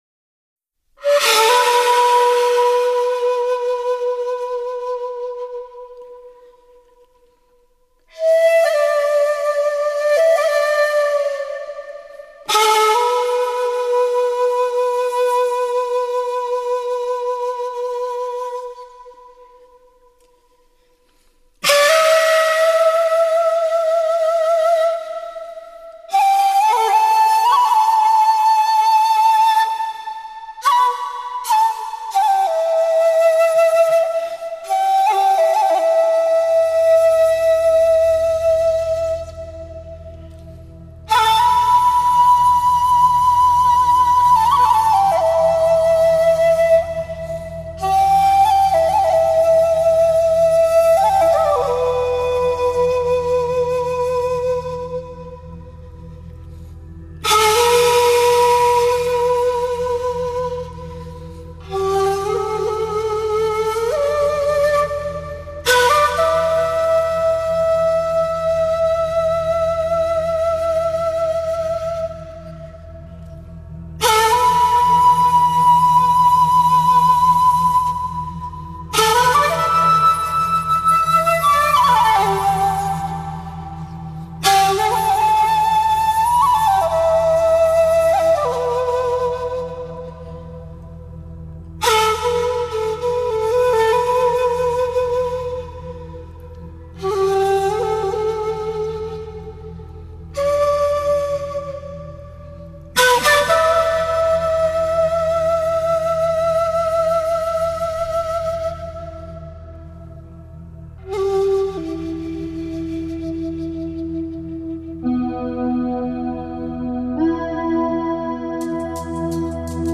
尺八、古筝和民谣